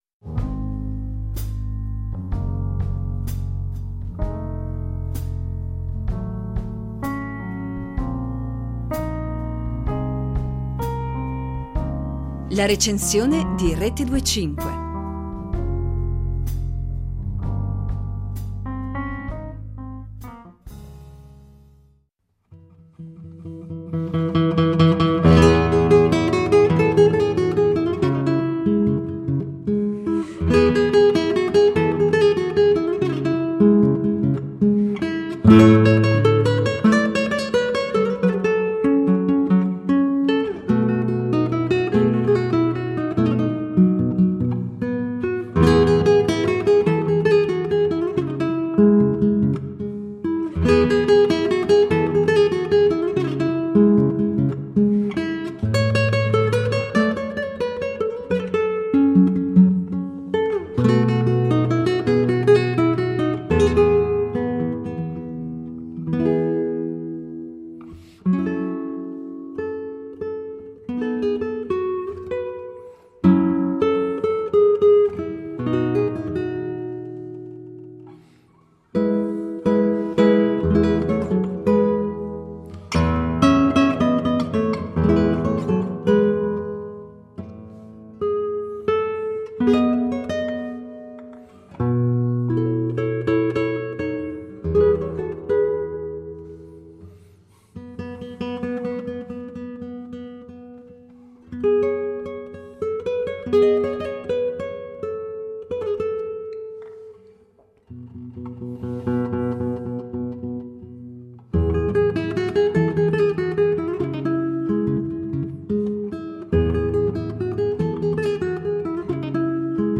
Uno stile vicino alle inflessioni impressioniste che si esprime in brani di breve durata, nei quali vengono evocati paesaggi e stilemi popolari, a disegnare un mondo di affetti intimo e al tempo stesso universale. Il linguaggio musicale propone infatti uno stile di facile comprensione, di carattere consolatorio, estraneo alla proposta delle avanguardie musicali, entro il quale l'intera cultura musicale spagnola risuona con equilibrata semplicità e autentica schiettezza.